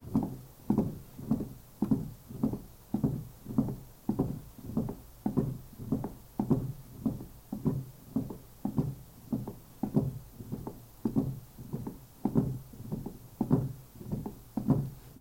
Тихий и плавный звук качания кресла без скрипа